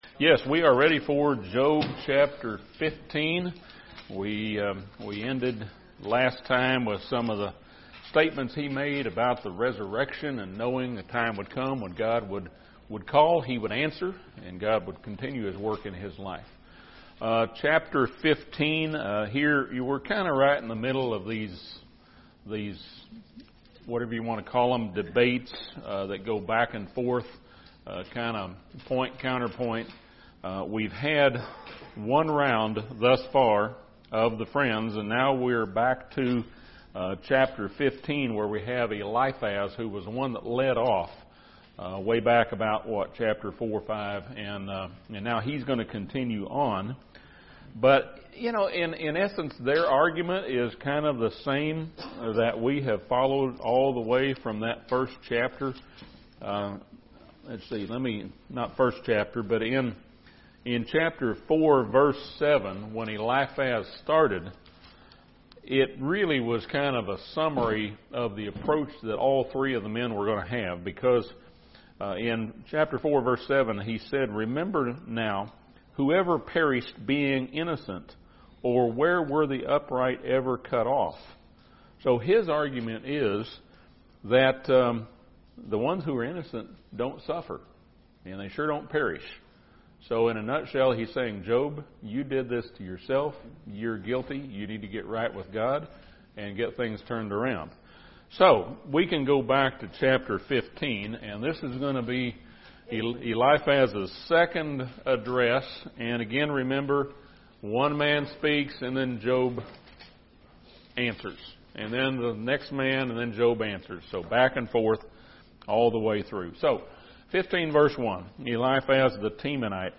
This Bible study surveys chapters 15-21 of Job.